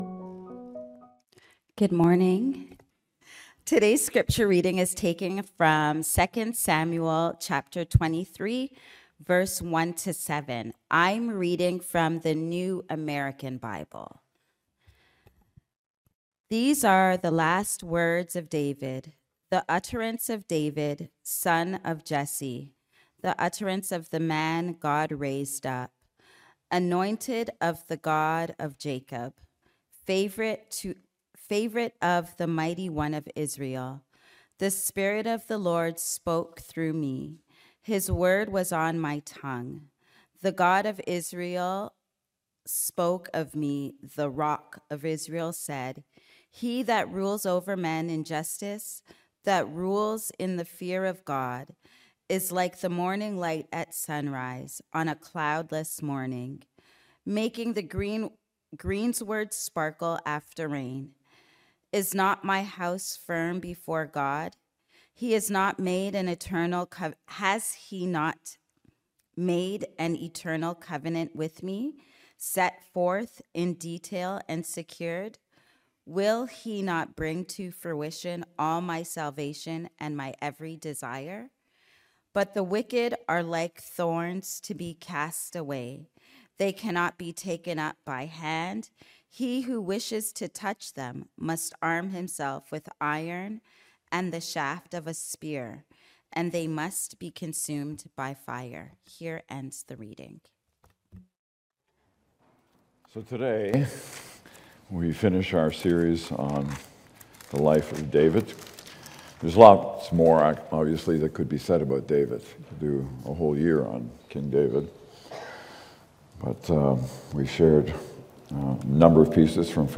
Sermons | Weston Park Baptist Church